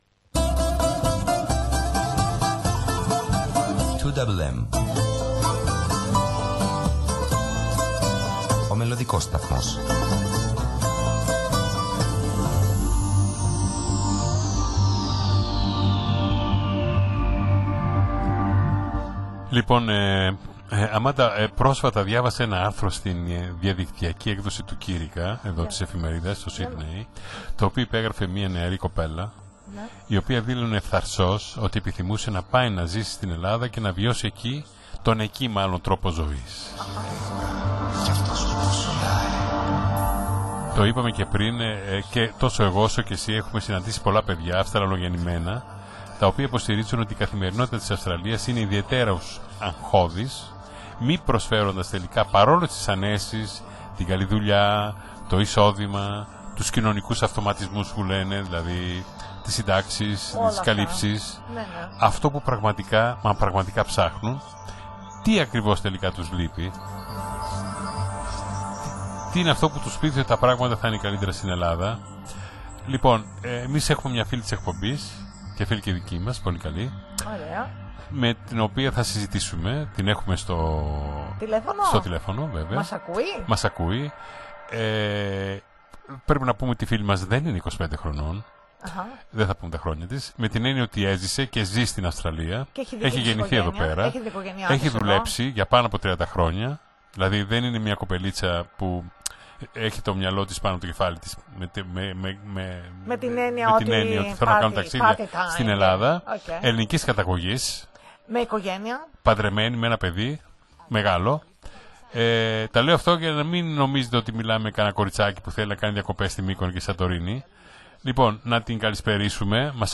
στην ζωντανή συζήτηση